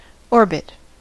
orbit: Wikimedia Commons US English Pronunciations
En-us-orbit.WAV